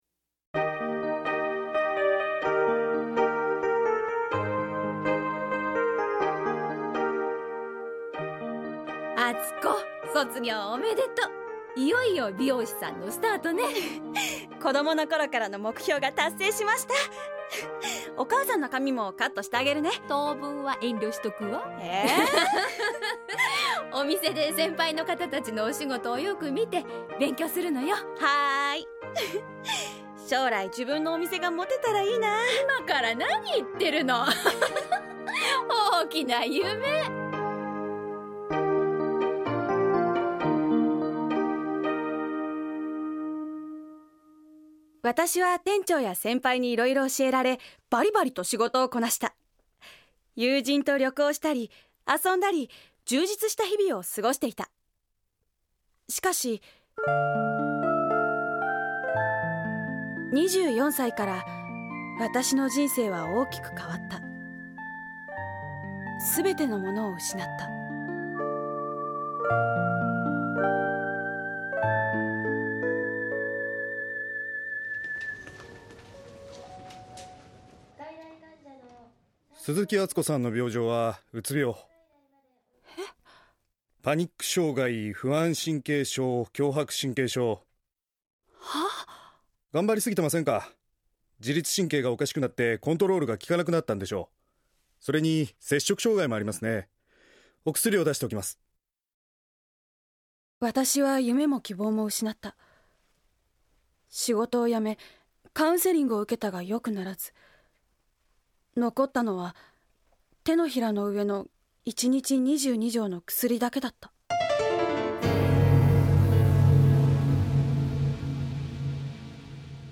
●ラジオドラマ「ようお参りです」
・教会の先生（若い・40歳・男性）
・医師（男性）